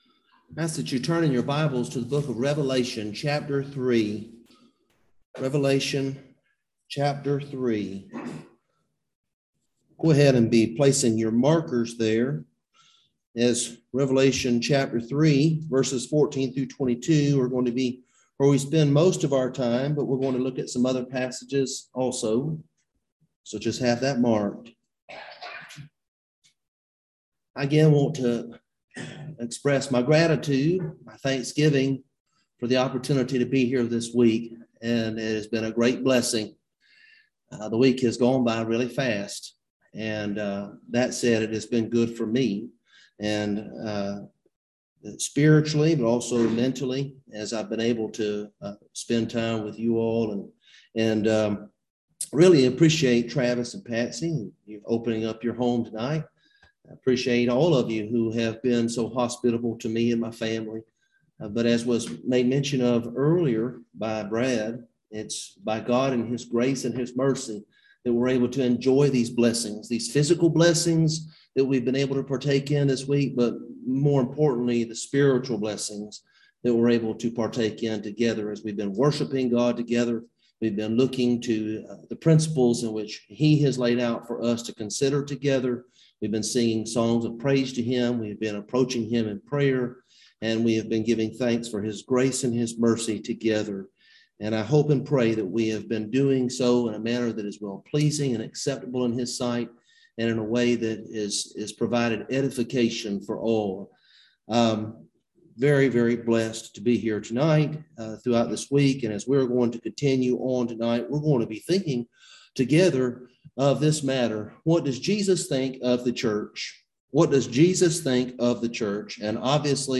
Passage: Revelation 3:14-22 Service Type: Gospel Meeting